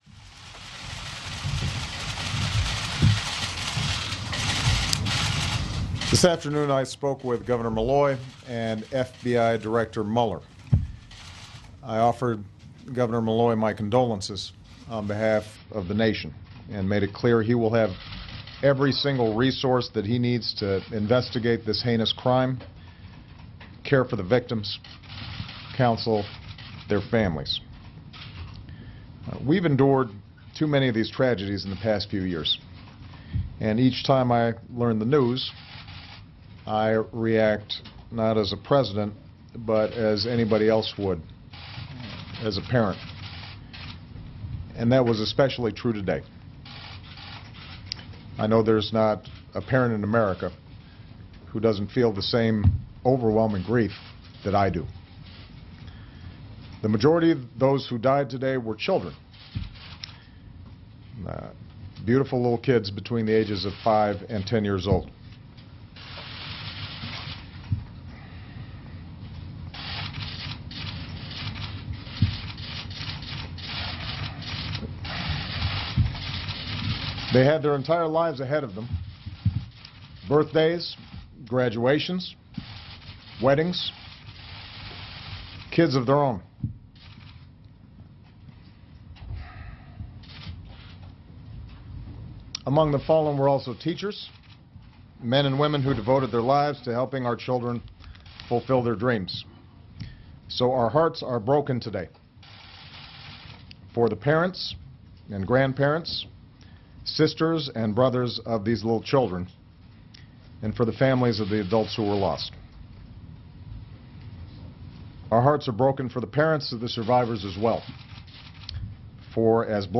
U.S. President Barack Obama speaks about the shooting at Sandy Hook Elementary School in Newtown, Connecticut